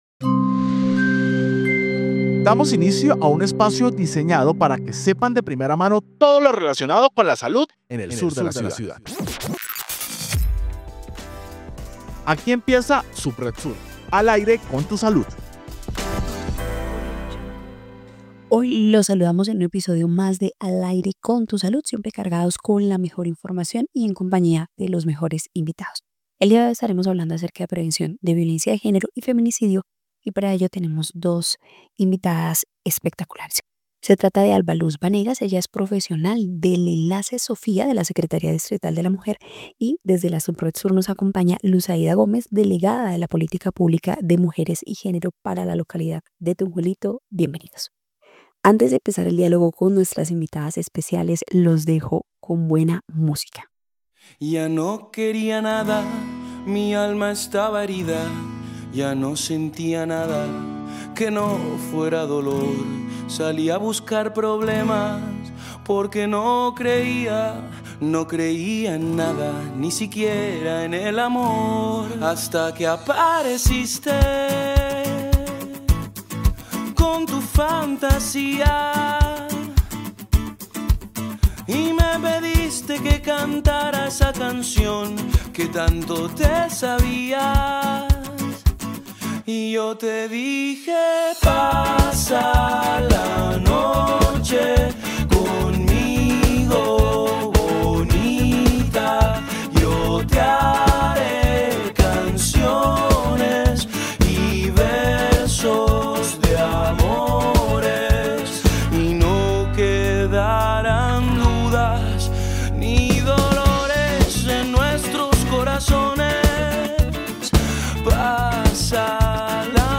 Programa Radial Subred Sur